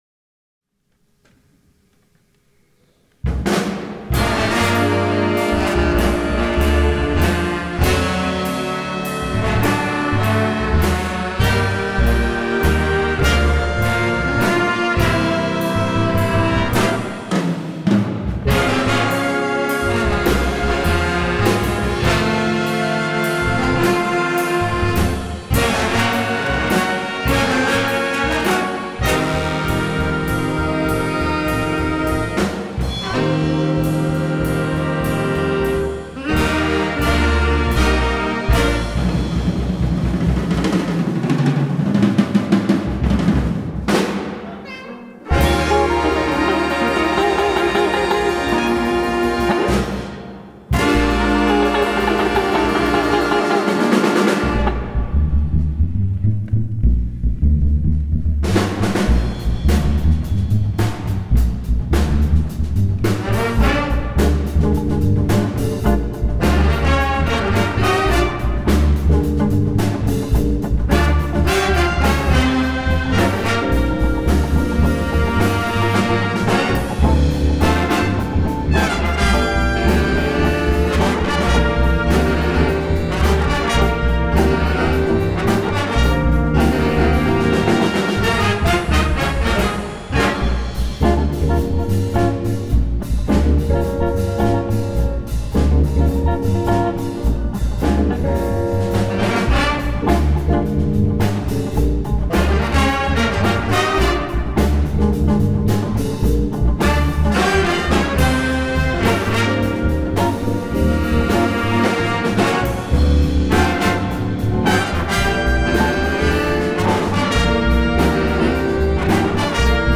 2018 Charter Oak Music Festival
Jazz Band